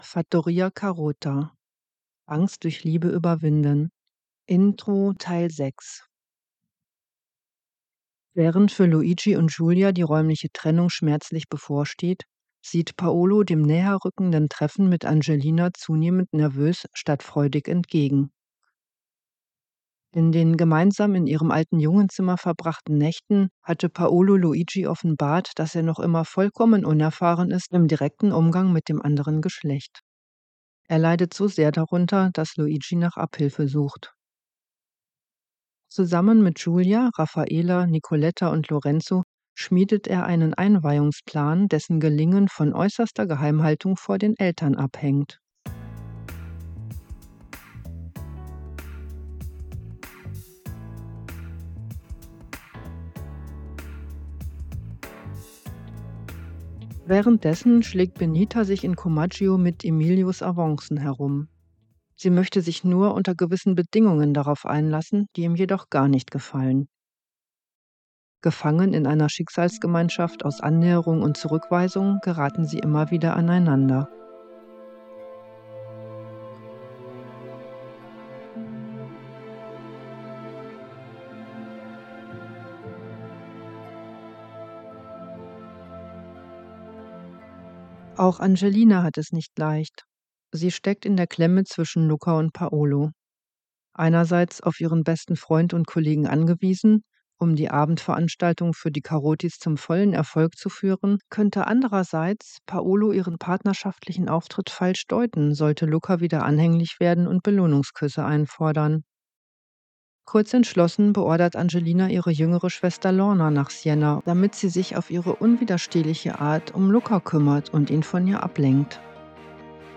Konflikte lösen mit Humor mit Wandel-Hörspielen (auch zum Mitmachen), ausgefallenen Gedichten, experimentellen Rollenspielen, Konfliktlösungs-Sketchen